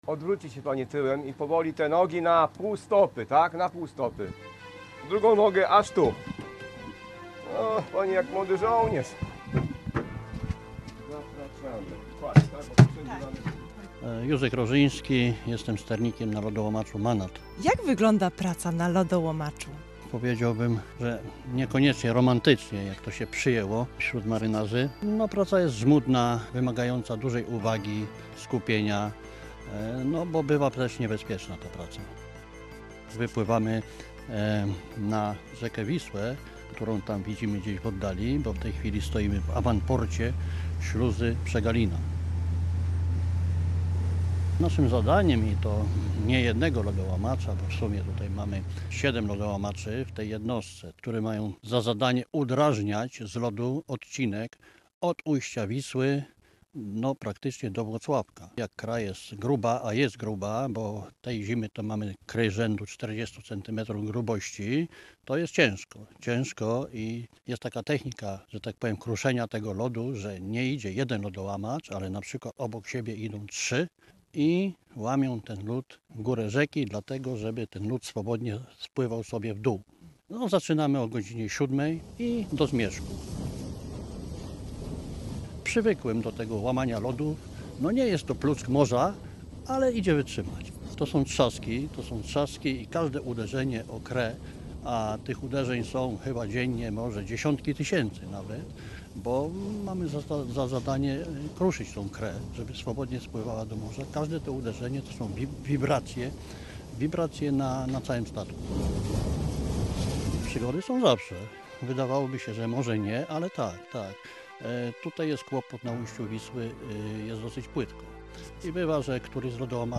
Dźwięk pękającej pod ciężarem lodołamaczy kry jest bardzo głośny…